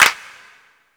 • Hand Clap Sound C# Key 23.wav
Royality free clap single shot - kick tuned to the C# note. Loudest frequency: 2817Hz
hand-clap-sound-c-sharp-key-23-5vW.wav